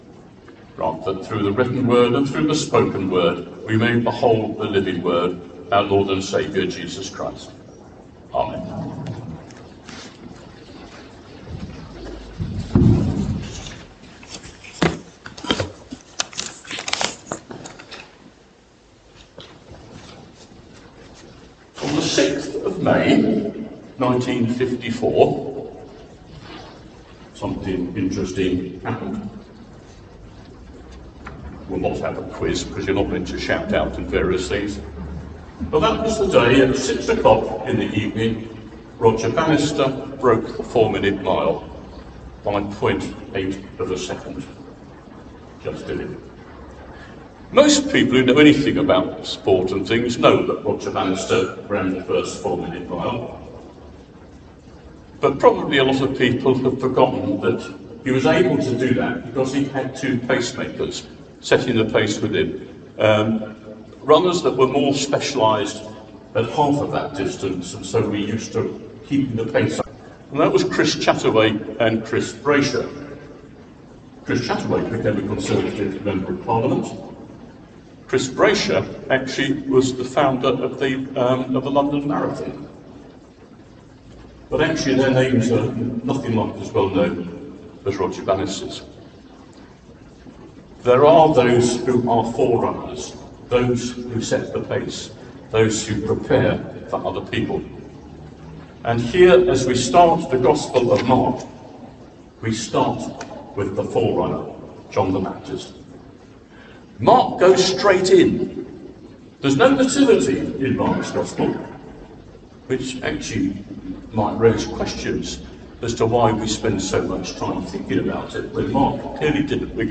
Sermon: Prepare for a new thing | St Paul + St Stephen Gloucester